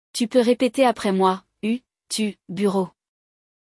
Para começar, é importante você saber que, em francês, o som do biquinho é uma espécie de meio termo entre o “u” e o “i”.
O som do biquinho é o som do “u” francês, que fica entre o “u” e o “i” do português.